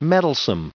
added pronounciation and merriam webster audio
1785_mettlesome.ogg